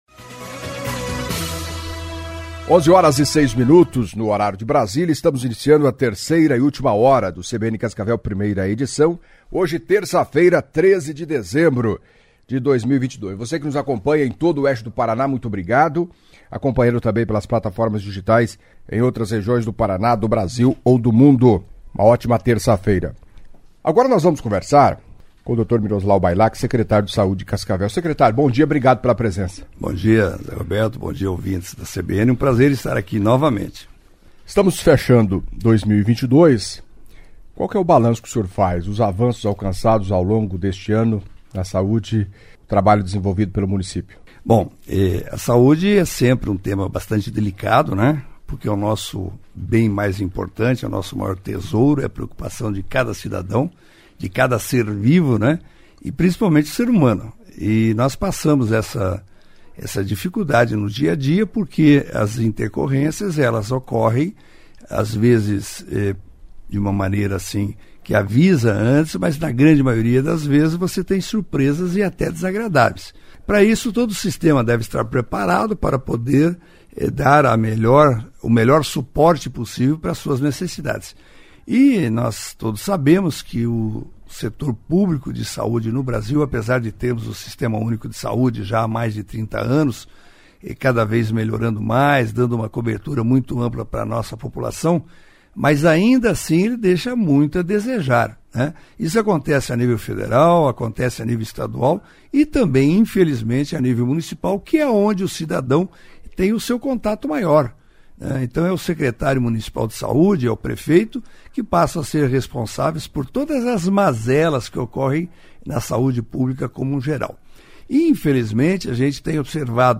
Em entrevista à CBN nesta terça-feira (13) o secretário de Saúde de Cascavel, Miroslau Bailak, avaliou positivamente os trabalhos conduzidos pelo município neste ano de 2022, destacando avanços importantes na área. Falou da falta de leitos hospitalares e das ocupações nas UPAs, segundo ele, ainda está acima do razoável ou aceitável, muita gente continua nas Unidades de Pronto Atendimento, aguardando leitos.